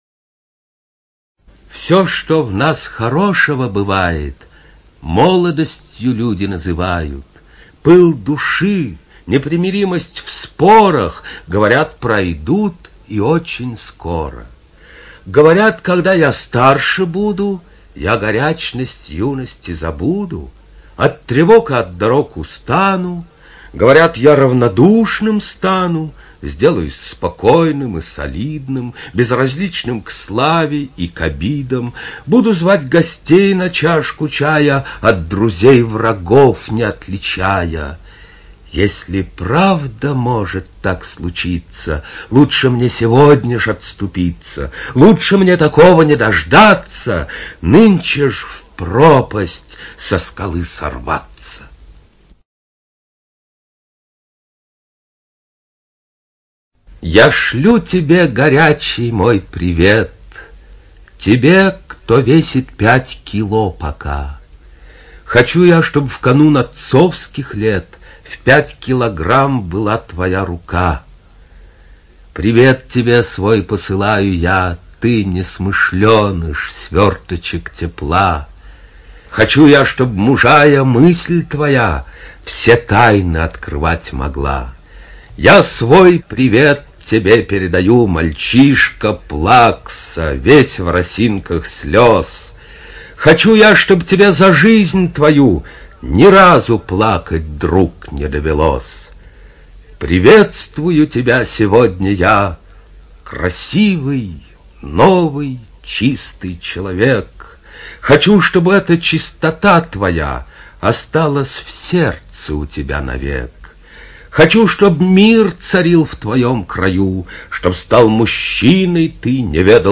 ЖанрПоэзия